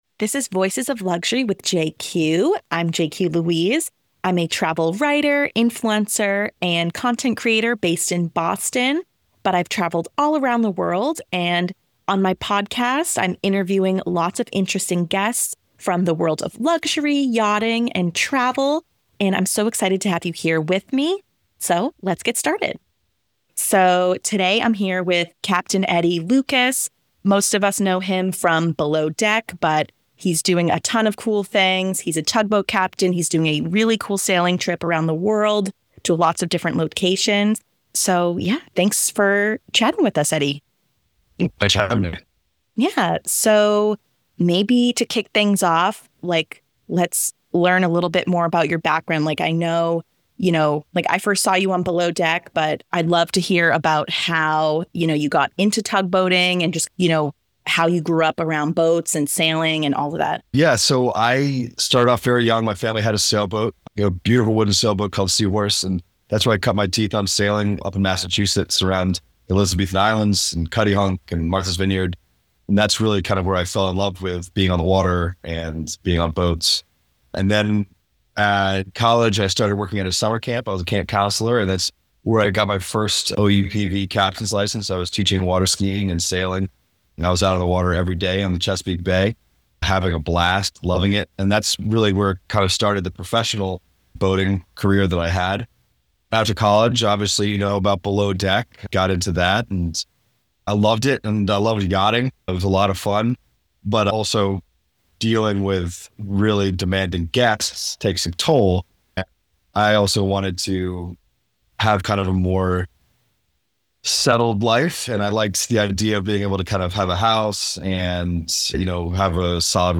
If you're interested in luxury with integrity, this conversation anchors deep.